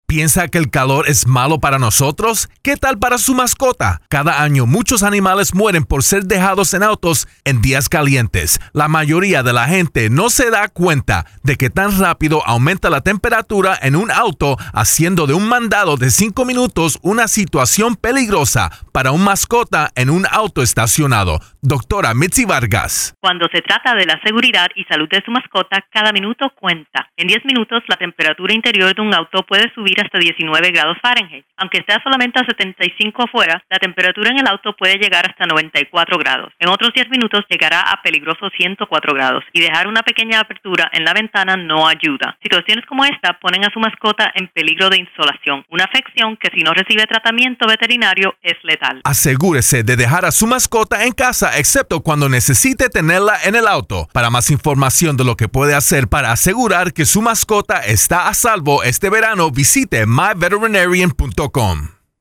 July 5, 2012Posted in: Audio News Release